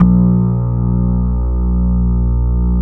ESQ 1 E-BASS.wav